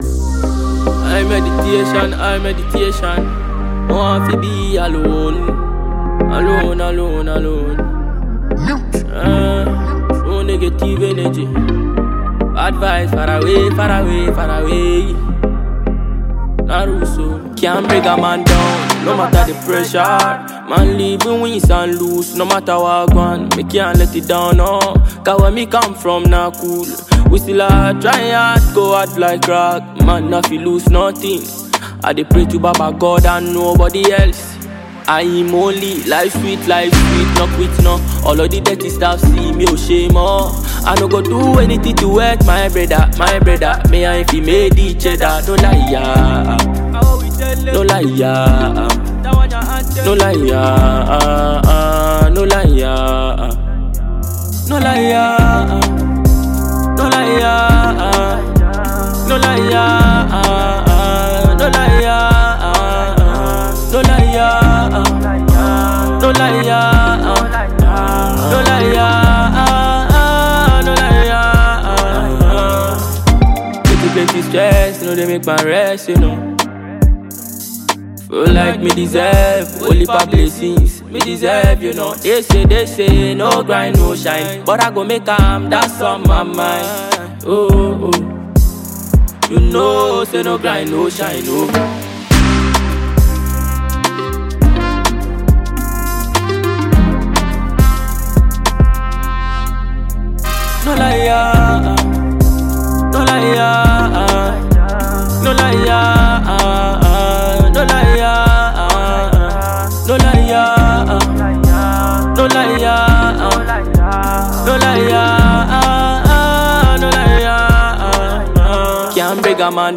Ghanaian dancehall act